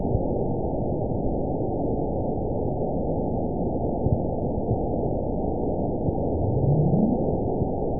event 920918 date 04/15/24 time 14:54:03 GMT (1 year, 2 months ago) score 8.24 location TSS-AB10 detected by nrw target species NRW annotations +NRW Spectrogram: Frequency (kHz) vs. Time (s) audio not available .wav